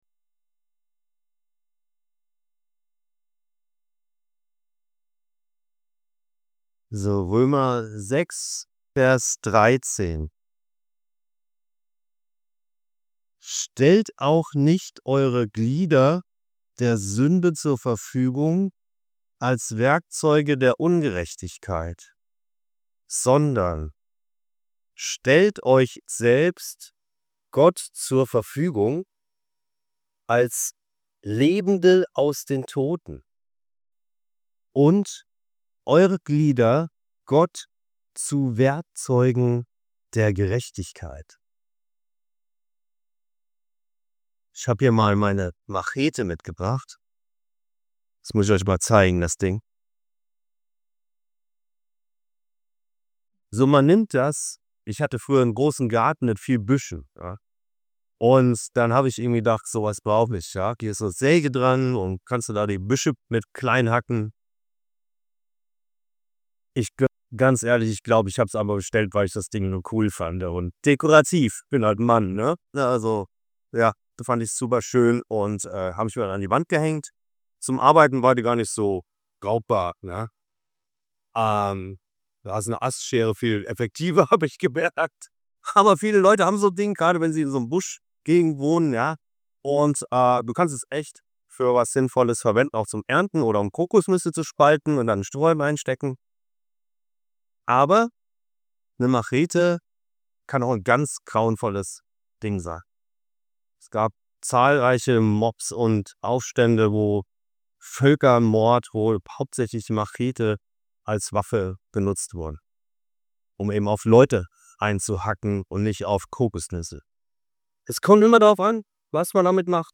Beschreibung vor 8 Monaten (Hinweis: die Audio wurde mit KI nachbeabeitet und ist bei min. 18 etwas unverständlich.)
Paulus macht klar: Unser Körper ist nicht der Sünde ausgeliefert, sondern kann zum Werkzeug der Gerechtigkeit werden. Diese Predigt zeigt, warum dein Körper für Gott so wertvoll ist – und wie du ihn im Alltag bewusst in seinen Dienst stellen kannst.